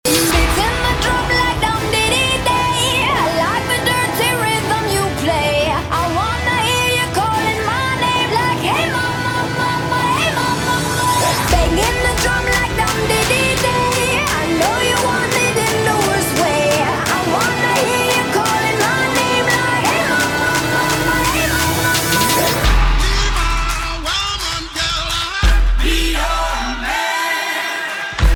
is a high-energy dance-pop track
infectious chorus and upbeat tempo